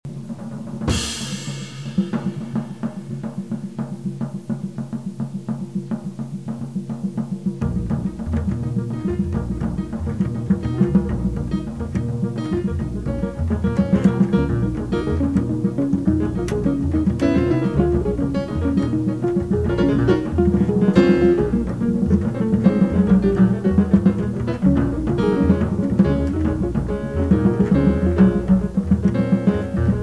chitarra
pianoforte
contrabbasso
batteria